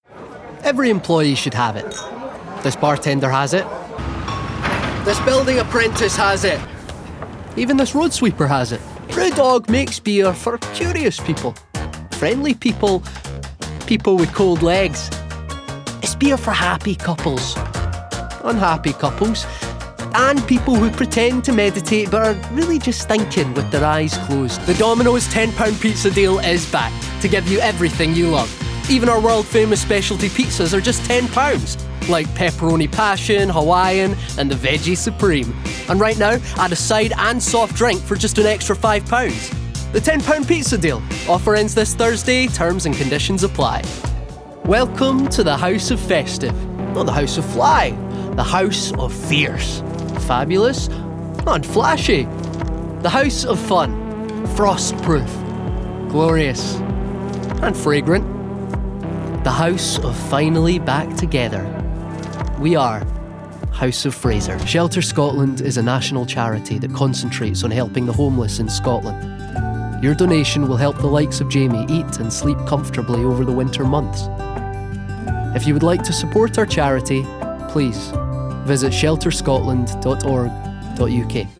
Commercial Reel
Scottish
Commercial, Bright, Upbeat, Conversational